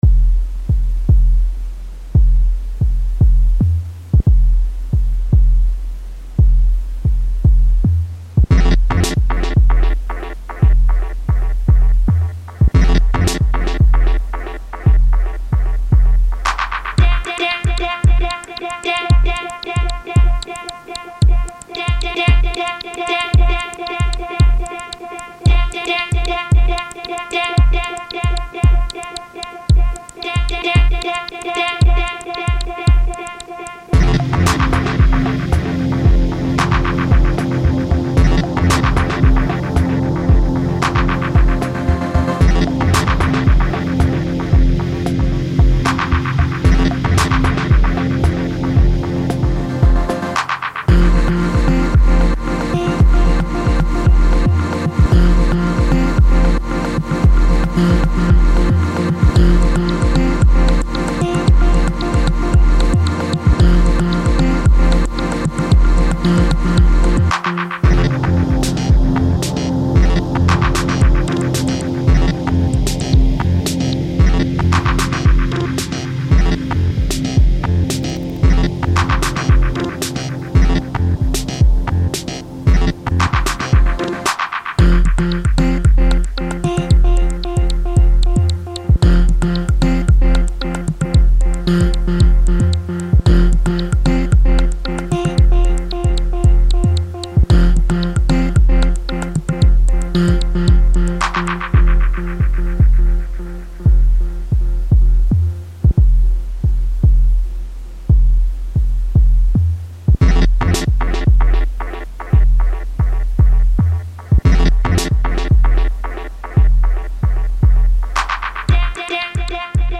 a vibey ambient remix